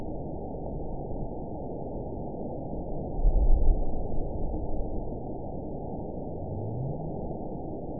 event 920551 date 03/30/24 time 00:38:28 GMT (1 year, 1 month ago) score 9.59 location TSS-AB01 detected by nrw target species NRW annotations +NRW Spectrogram: Frequency (kHz) vs. Time (s) audio not available .wav